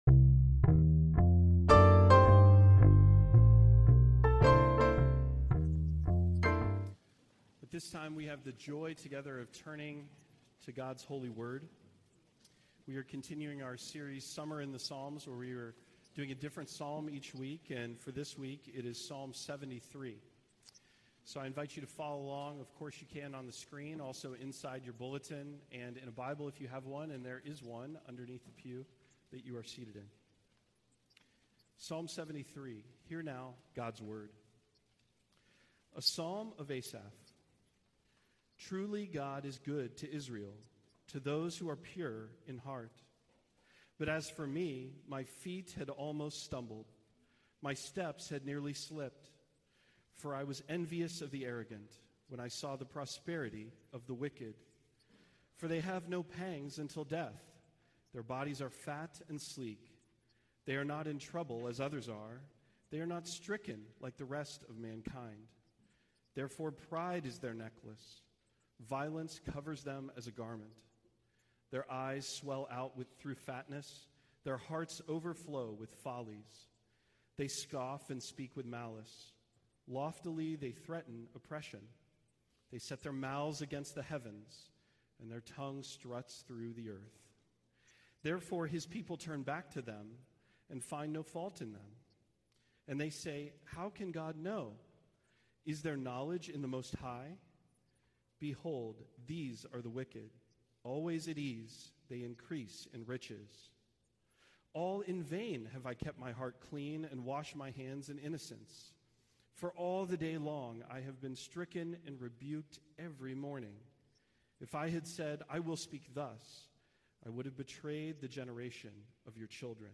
Passage: Psalm 73 Service Type: Sunday Worship